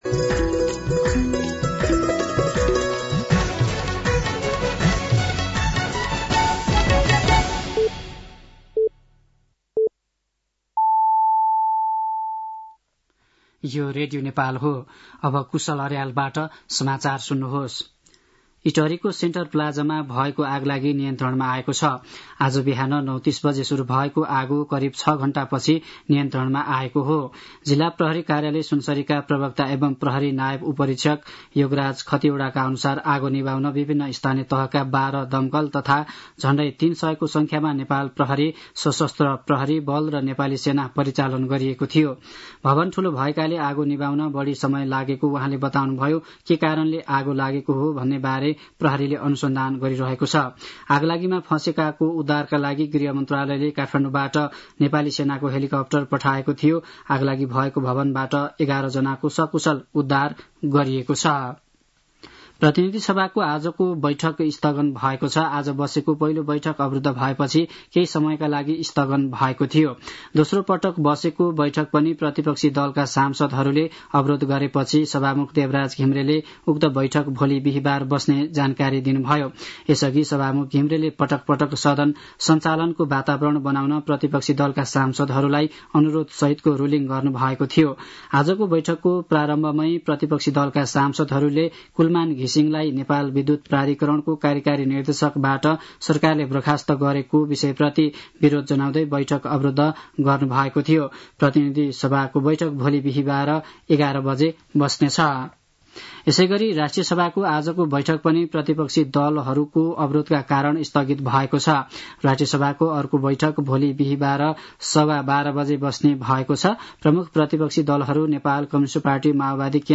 An online outlet of Nepal's national radio broadcaster
साँझ ५ बजेको नेपाली समाचार : १३ चैत , २०८१